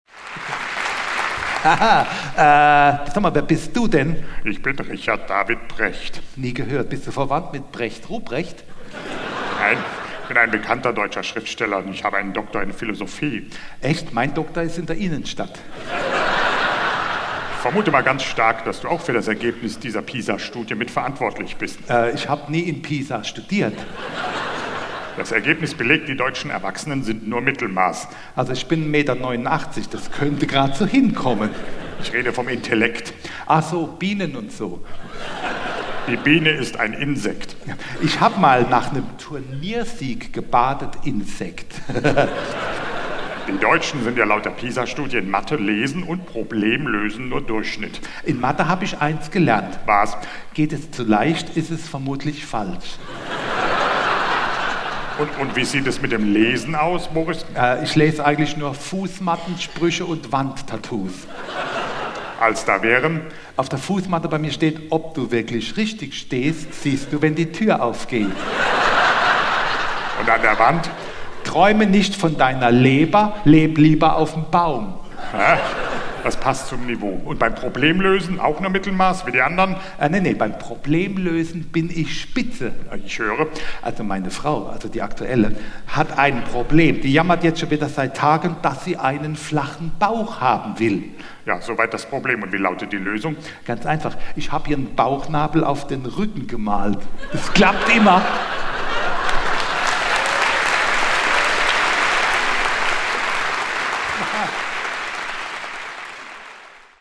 SWR3 Comedy Andreas Müller LIVE Boris und Precht zu Pisa-Leistungen von Erwachsenen
Wir Deutschen Erwachsenen sind wohl im Pisa-Vergleich auch nicht grade top. Was wäre, wenn Richard David Precht und Boris Becker dieses Thema mal erörtern würden - so geschehen bei Andreas Müller auf der LIVE-Bühne.